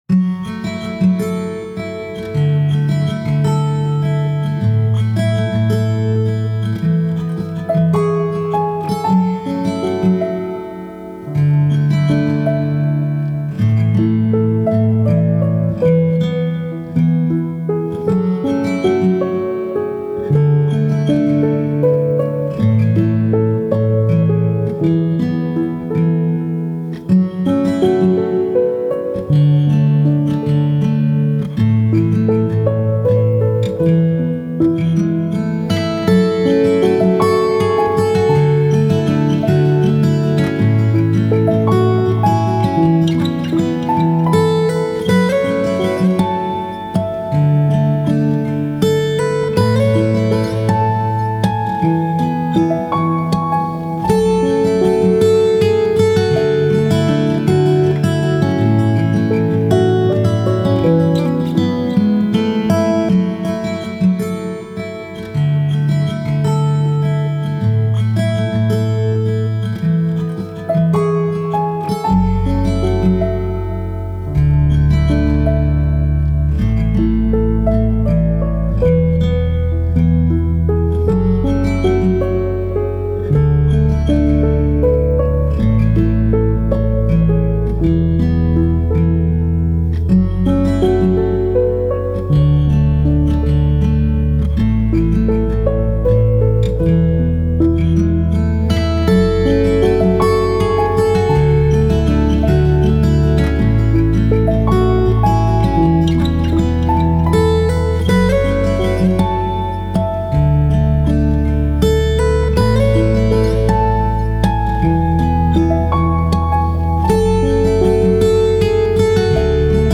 آرامش بخش گیتار موسیقی بی کلام